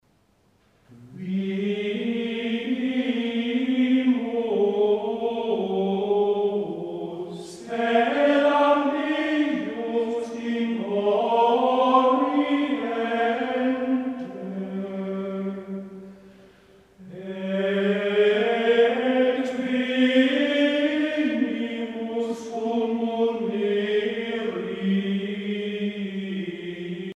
Musique médiévale
Pièce musicale éditée